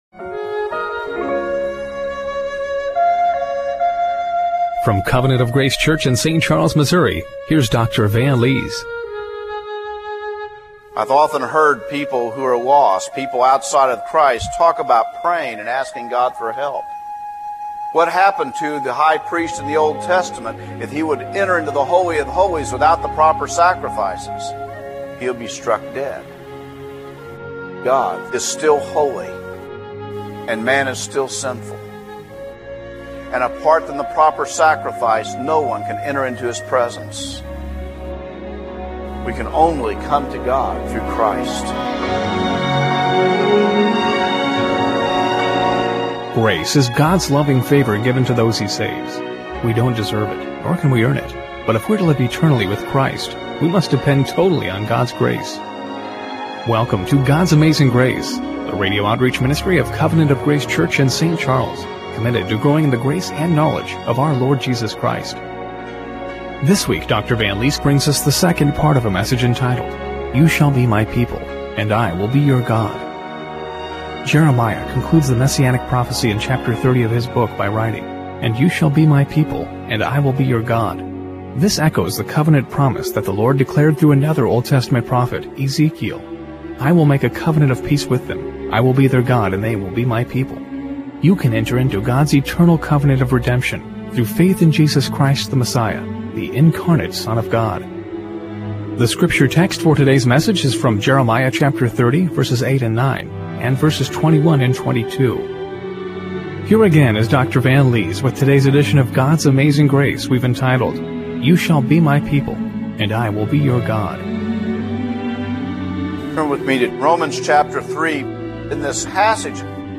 Jeremiah 30:21-22 Service Type: Radio Broadcast Do you know how you can enter into God's eternal covenant of redemption?